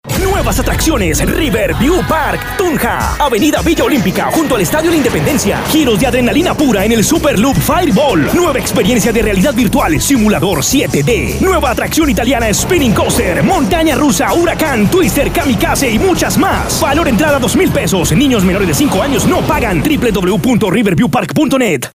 Voz comercial para radio